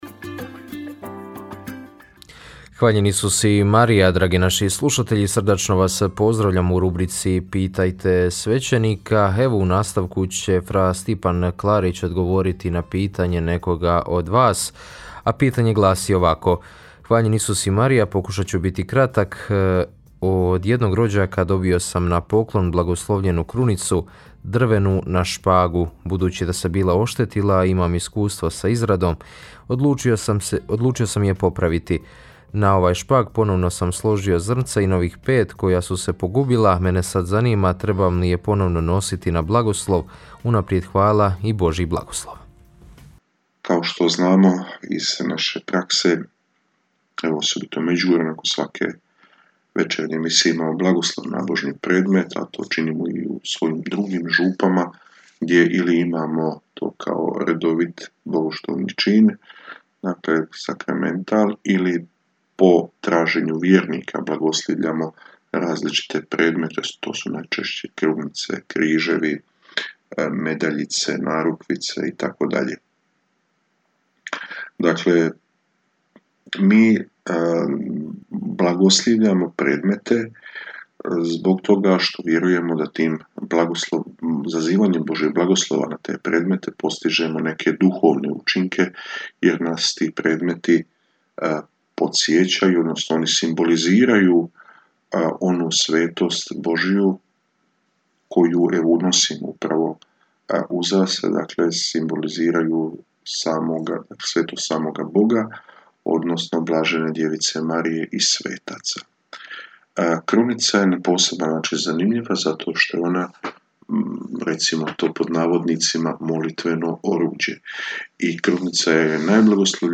U njoj na pitanja slušatelja odgovaraju svećenici, suradnici Radiopostaje Mir Međugorje.